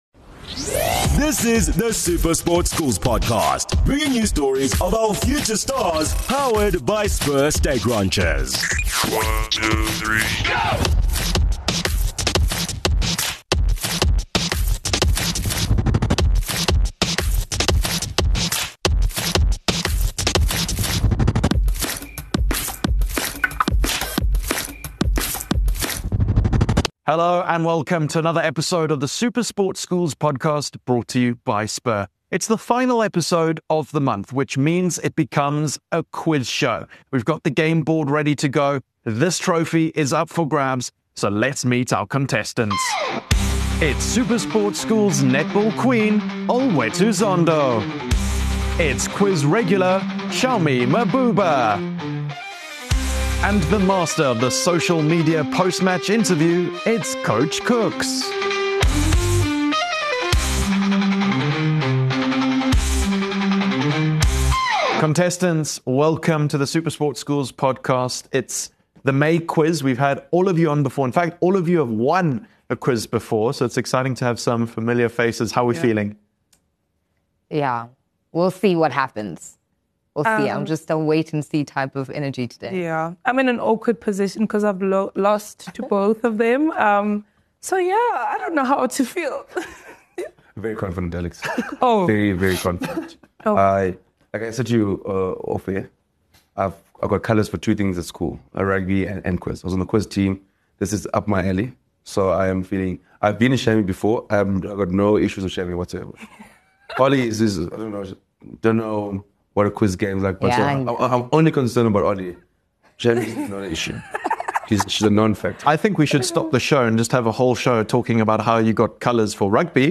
Delve into exclusive interviews with legends of the game, rising talents, dedicated coaches, and the schools that nurture future champions.